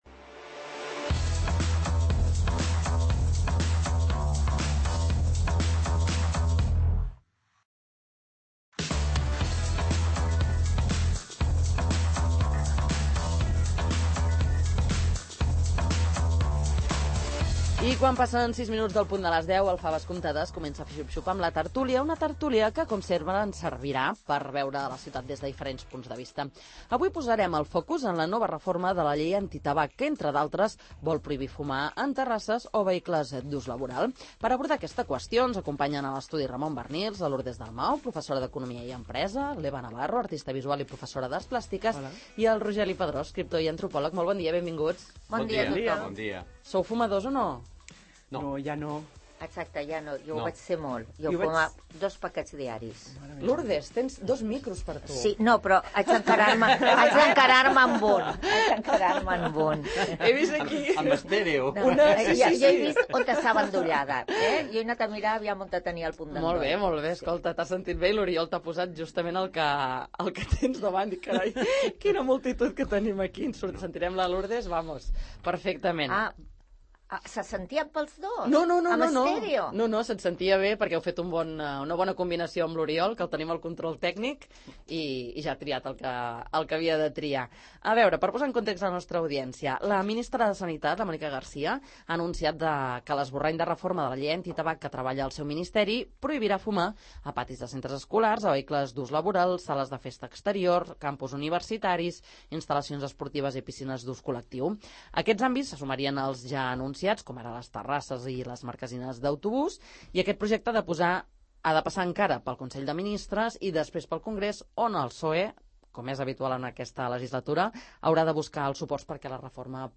El pla per endurir la llei contra el tabac ser� efectiu? En parlem a la tert�lia del 'Faves comptades'